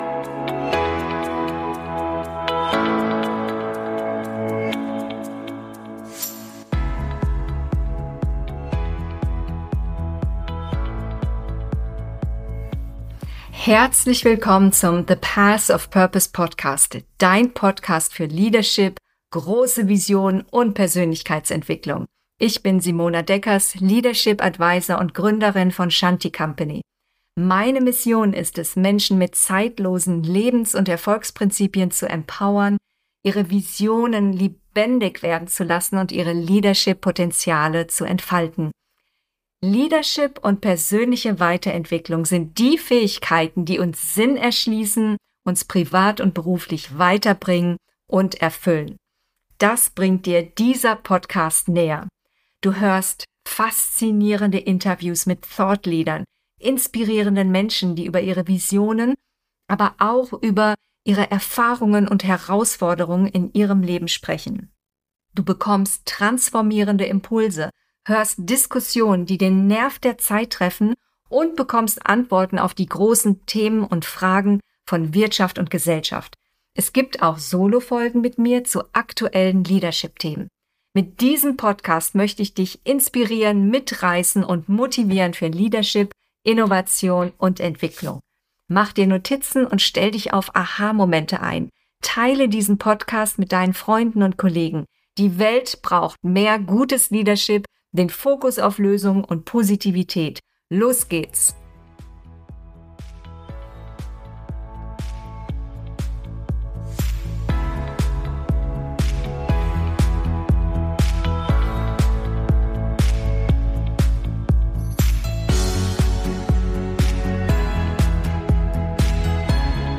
Erfolgsfaktor Radikale Freundlichkeit – Interview